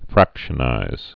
(frăkshə-nīz)